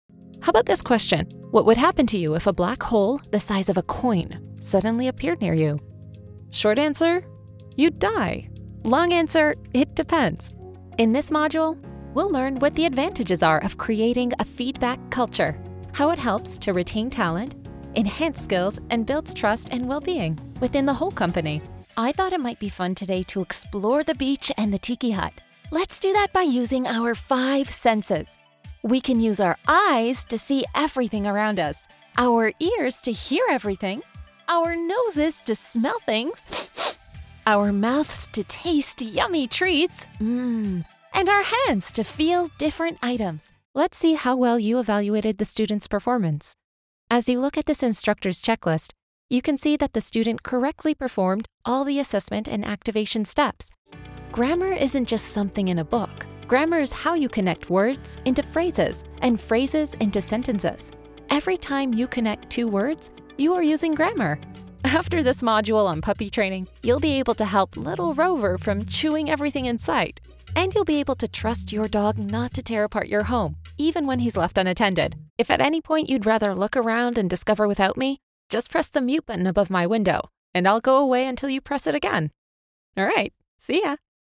E-learning
Rode NT 1, SSL2 Interface, Reaper, Custom Built Studio PC, Sound Booth, Live Direction
Mezzo-SopranoSoprano